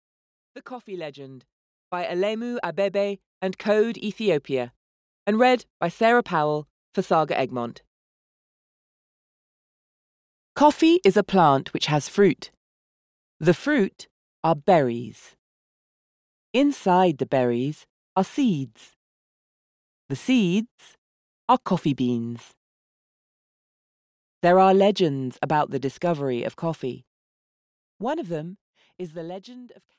The Coffee Legend (EN) audiokniha
Ukázka z knihy